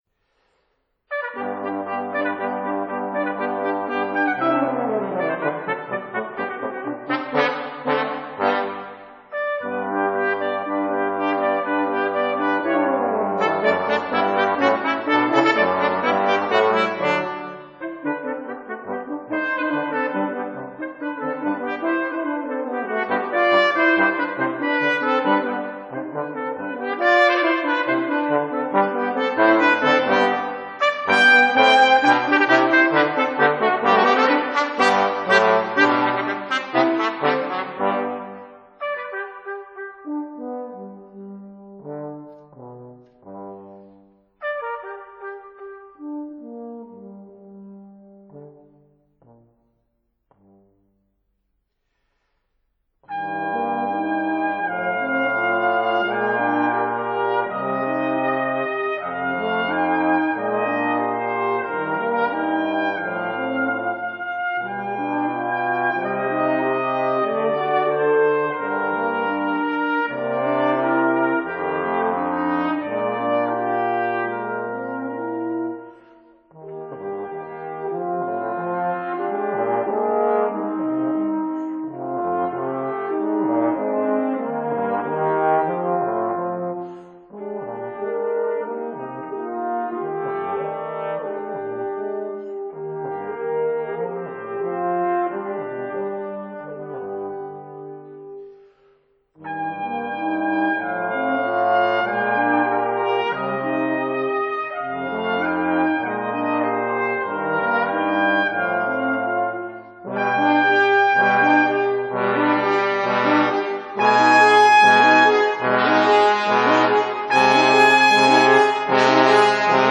Allegro moderato
Andante